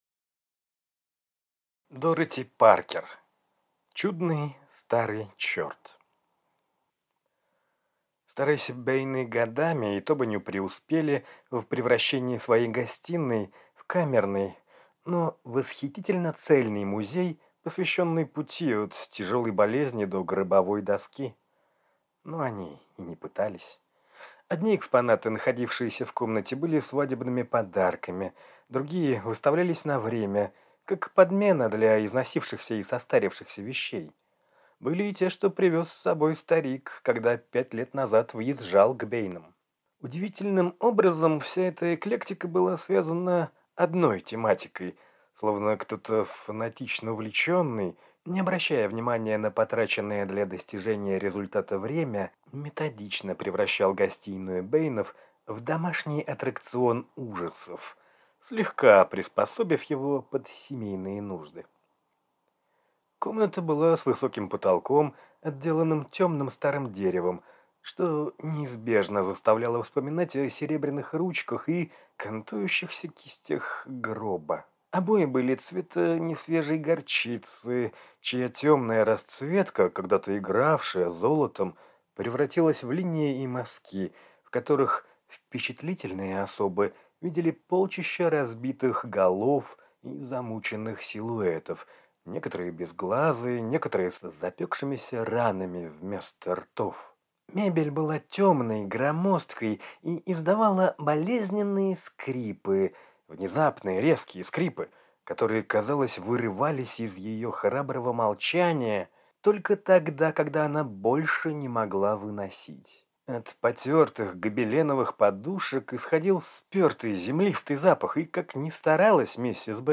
Аудиокнига Чудный Старый чёрт | Библиотека аудиокниг
Прослушать и бесплатно скачать фрагмент аудиокниги